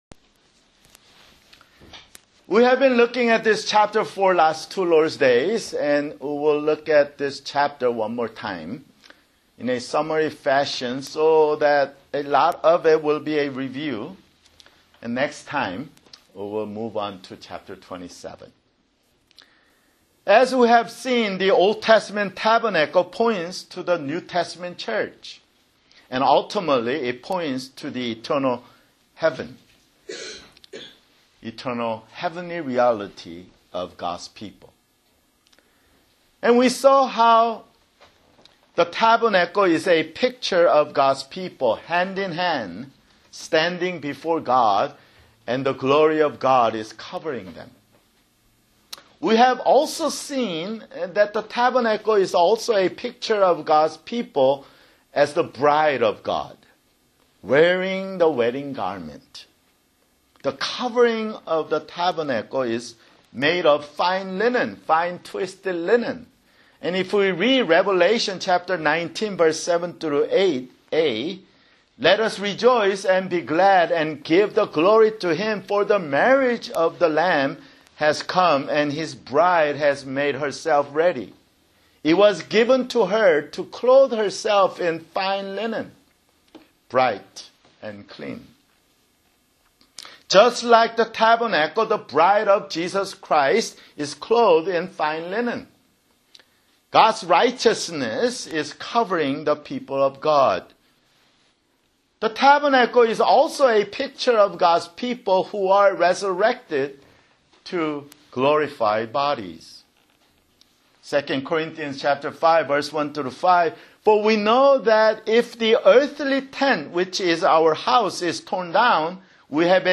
[Sermon] Exodus (79)